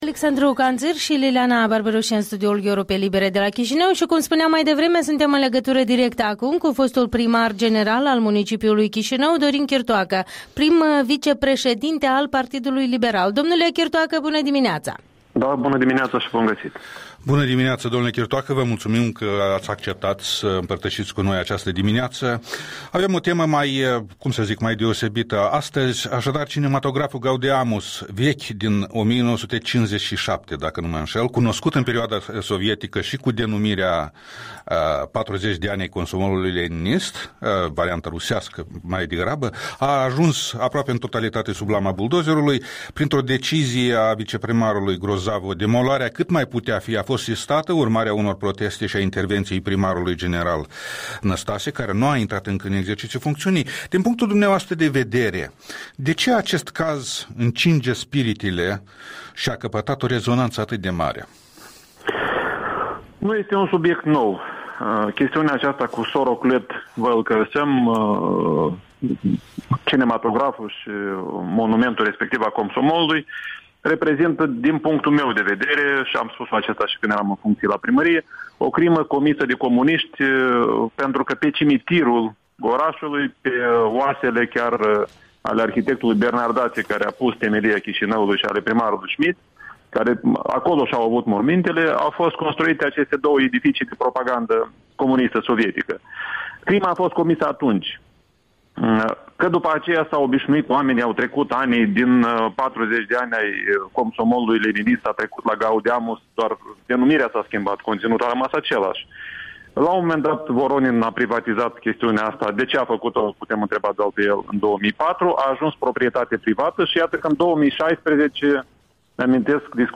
Interviul dimineții cu fostul primar al Chișinăului.